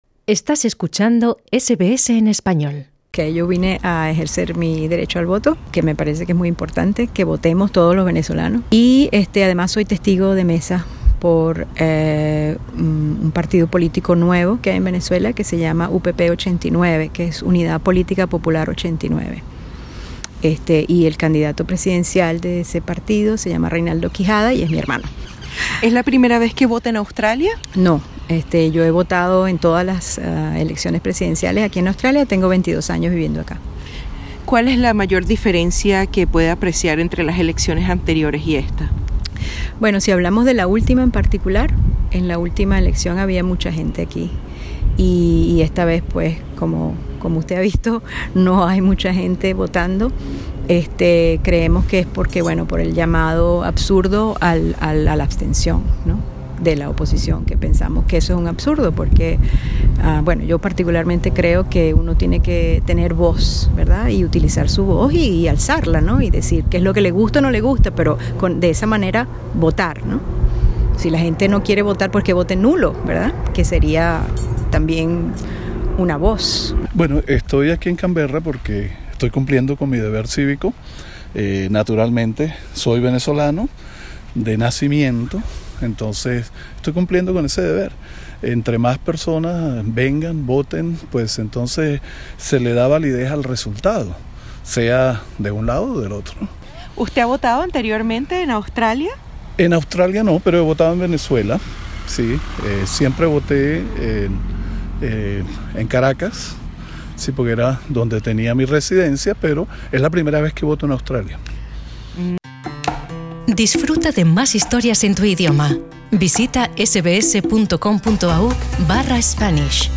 Dos venezolanos que acudieron a votar en la Embajada de Canberra opinan ante los micrófonos de SBS sobre este proceso electoral.